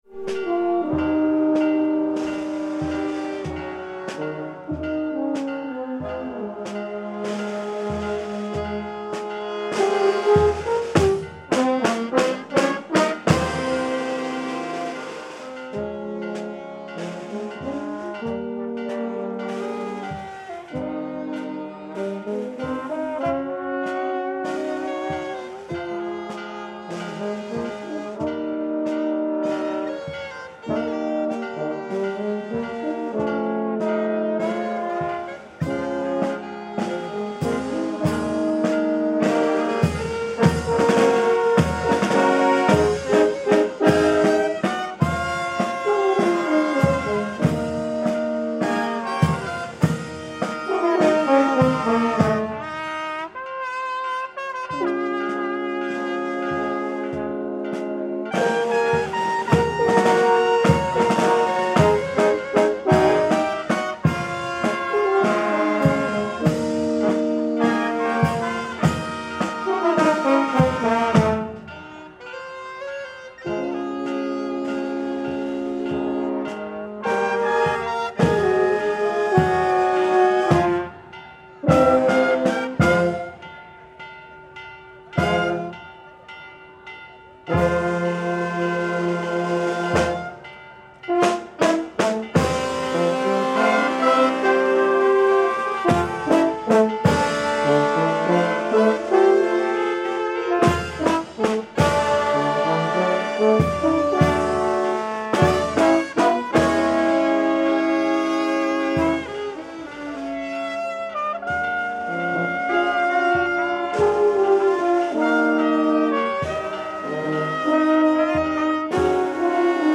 Religious procession in Lima
Stereo 48kHz 24bit.